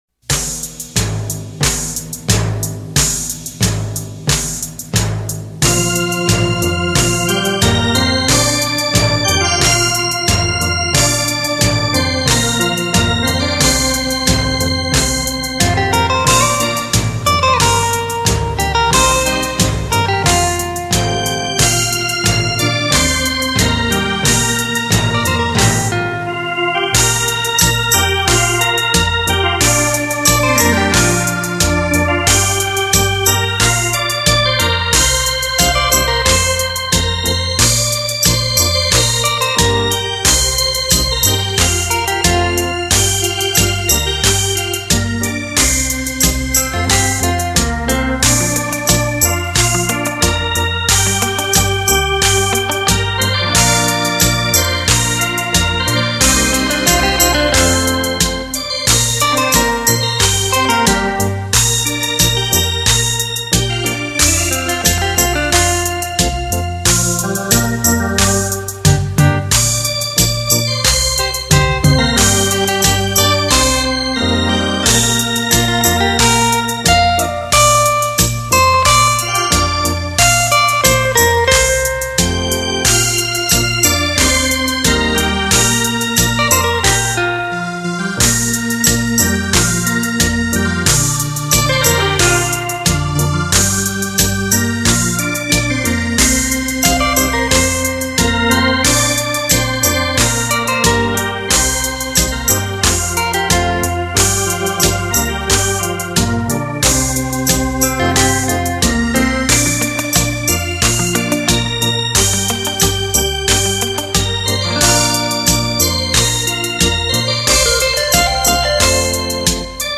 演绎细致传神 动态凌厉澎湃 制作完美无瑕
为低音质MP3